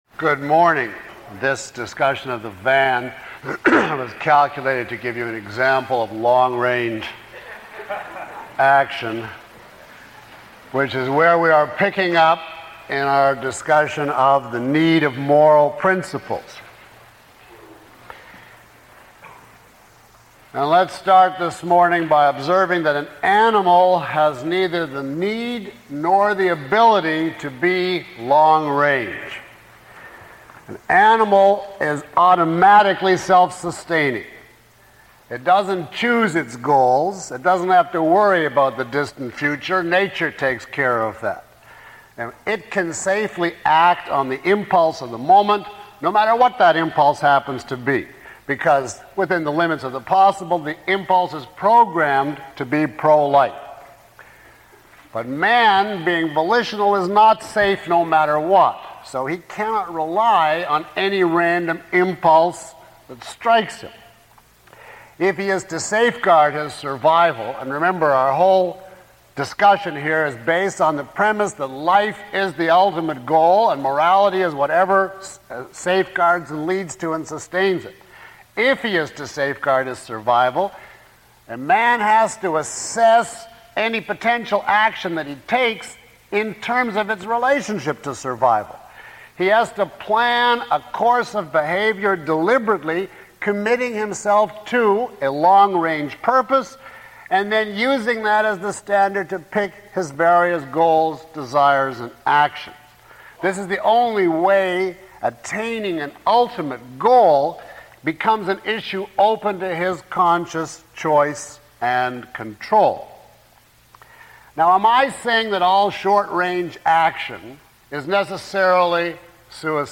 Lecture 05 - Objectivism - The State of the Art.mp3